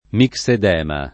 vai all'elenco alfabetico delle voci ingrandisci il carattere 100% rimpicciolisci il carattere stampa invia tramite posta elettronica codividi su Facebook mixedema [ mik S ed $ ma ; alla greca mik S$ dema ] s. m. (med.); pl.